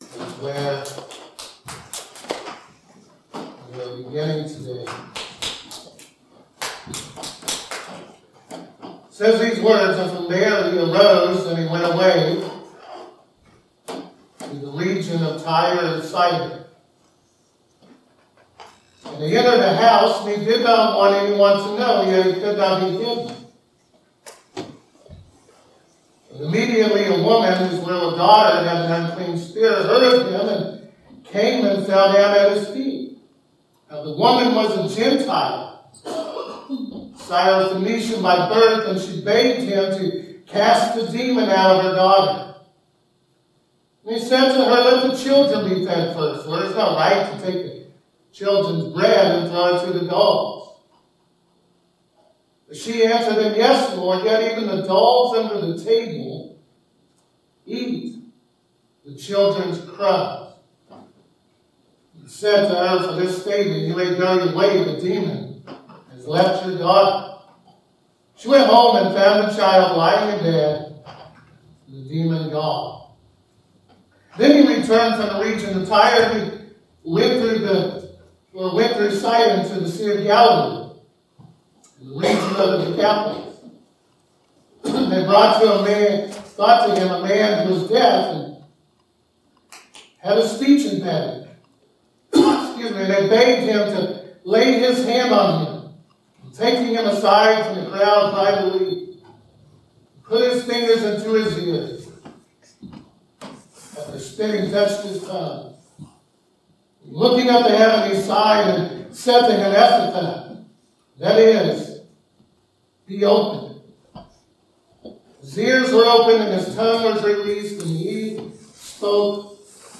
Mark 7:24-37 Service Type: Sunday Morning Mark 7:24-37.